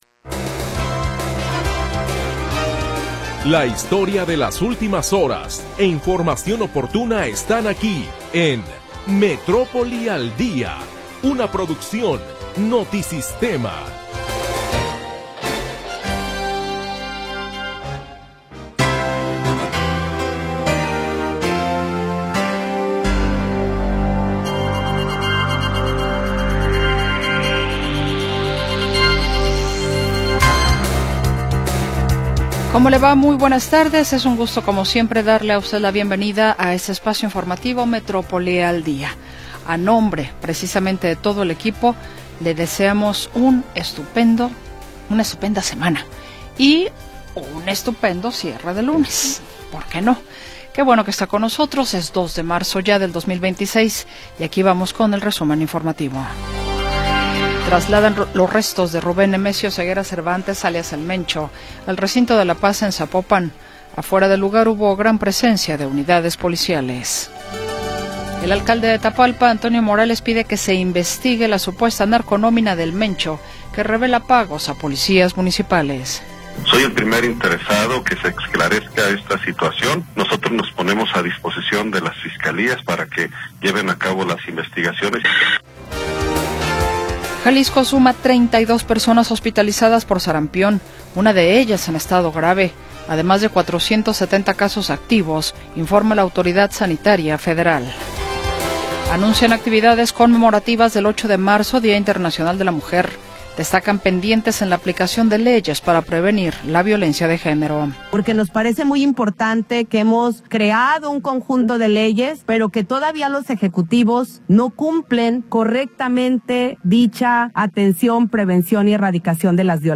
Primera hora del programa transmitido el 2 de Marzo de 2026.